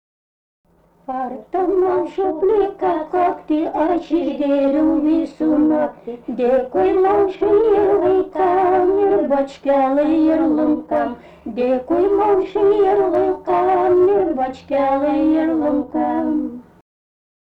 Subject daina
Erdvinė aprėptis Anykščiai
Atlikimo pubūdis vokalinis